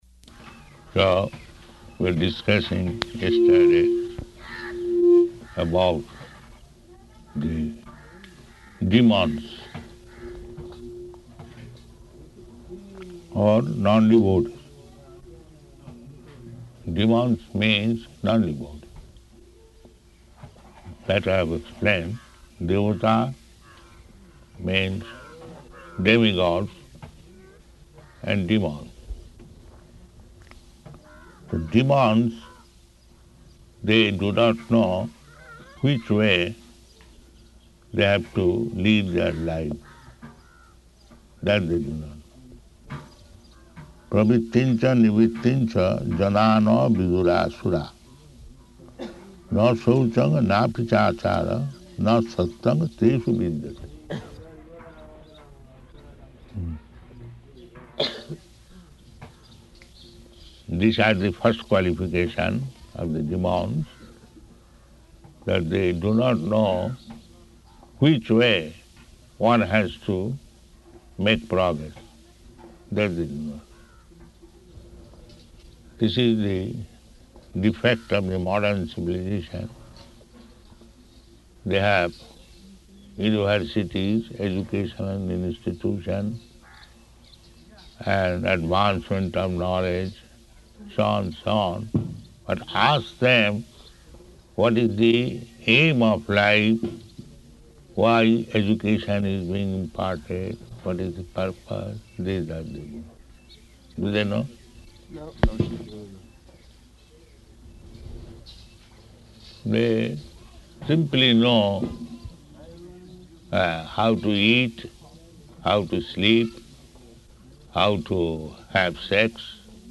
Bhagavad-gītā 16.7 --:-- --:-- Type: Bhagavad-gita Dated: December 14th 1976 Location: Hyderabad Audio file: 761214BG.HYD.mp3 Prabhupāda: So we were discussing yesterday about the demons, or nondevotees.